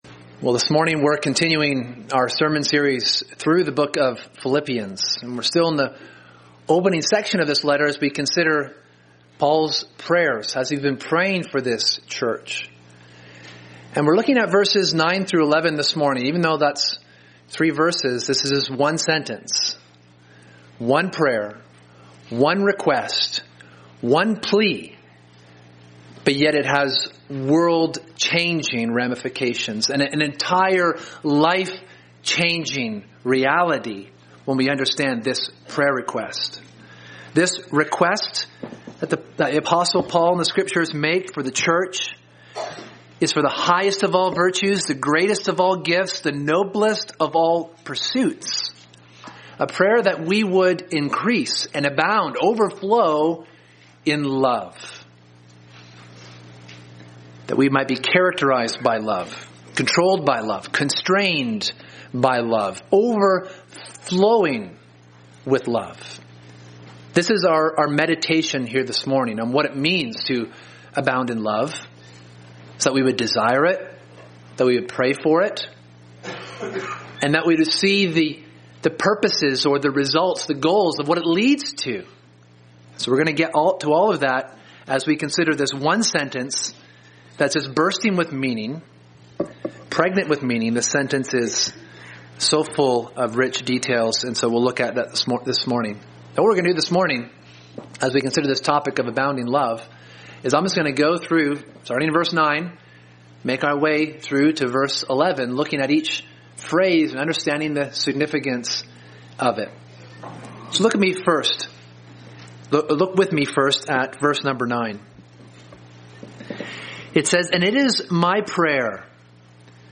Sermon: Abounding Love